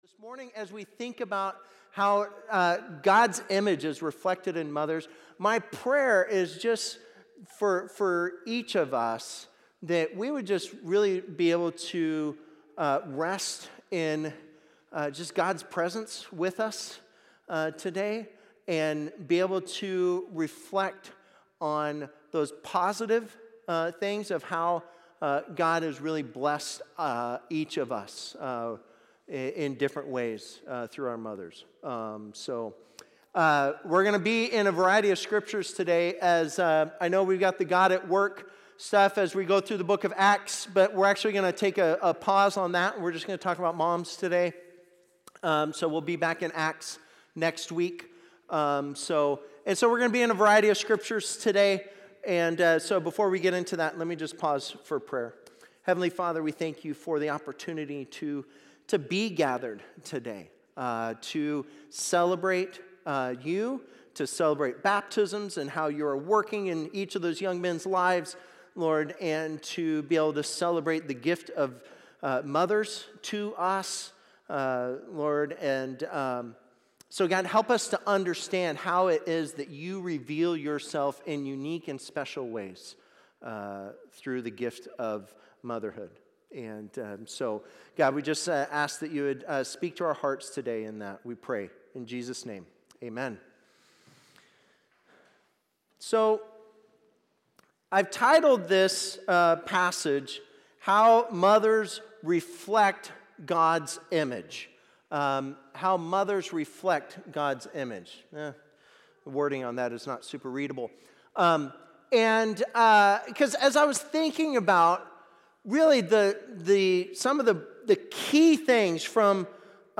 Sermons | Salt Creek Baptist Church